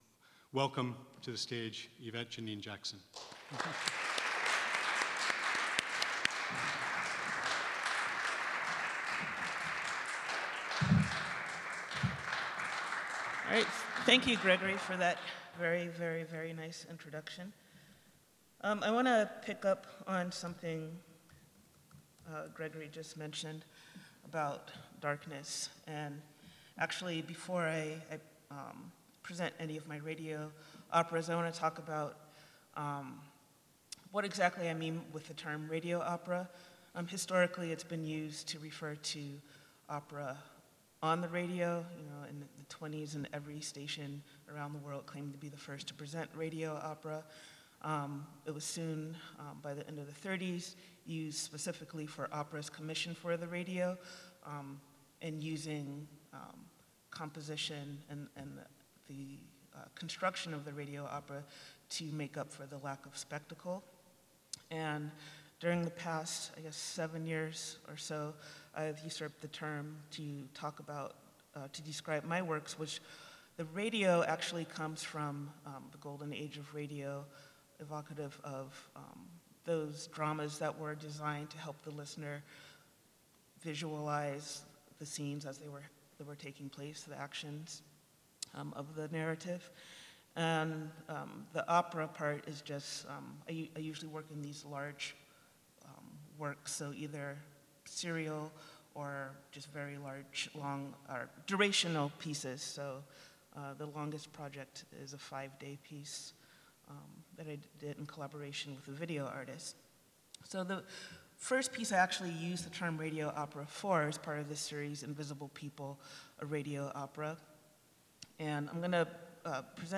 Distler Hall @ Granoff Music Center